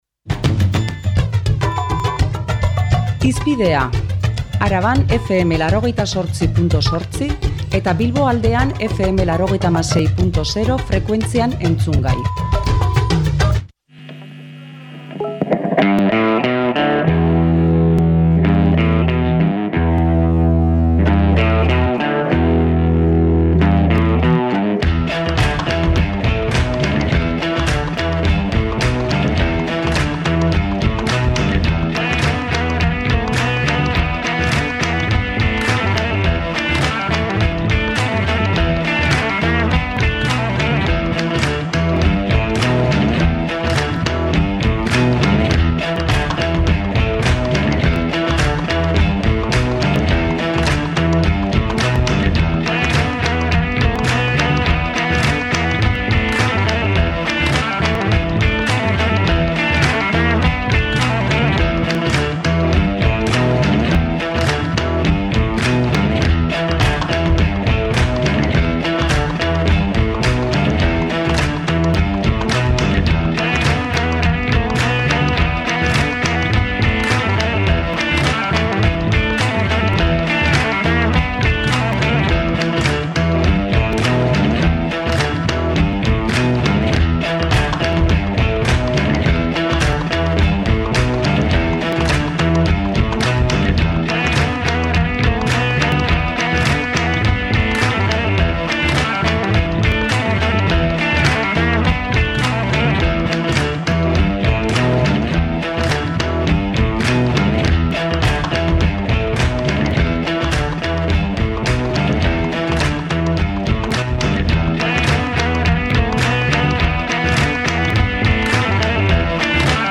Entzun dezagun gaurko saioaren hasiera: